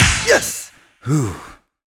goodClap1.wav